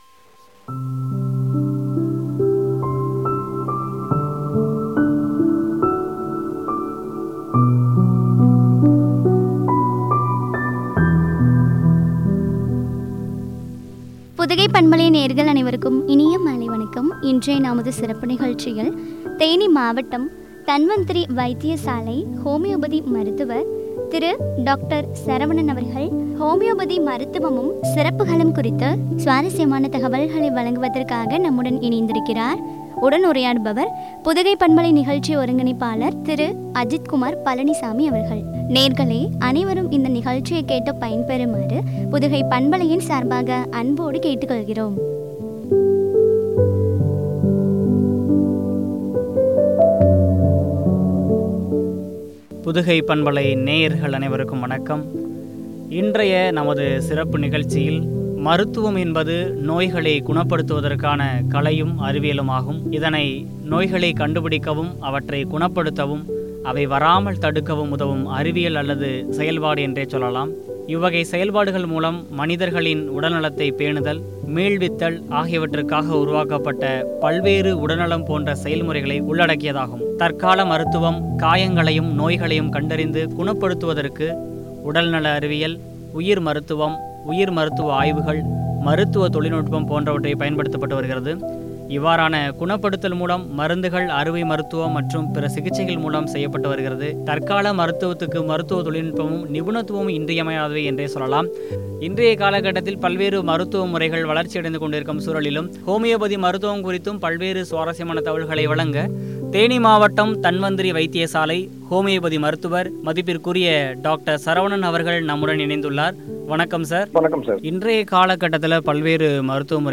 சிறப்புகளும் குறித்து வழங்கிய உரையாடல்.